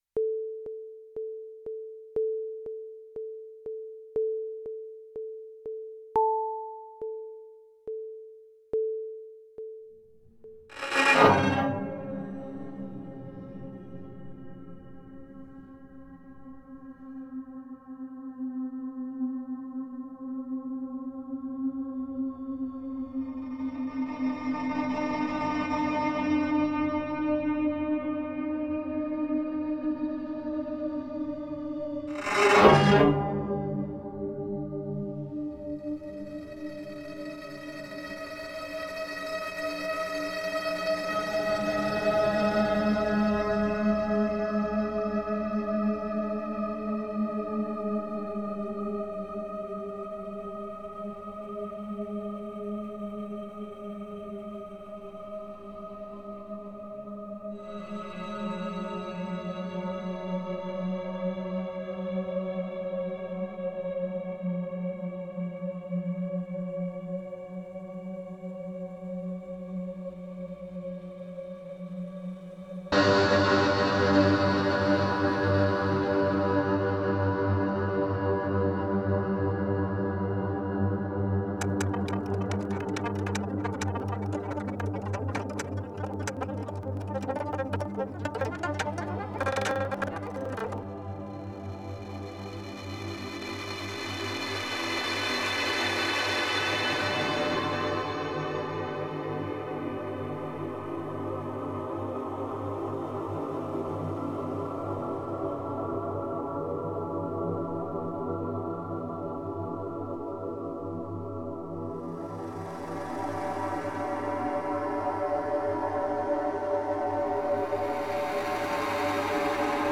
Gaya Rabbit is a composition for Gayageum + live electronics (tape and max/MSP). The prepared audio track is available for listening.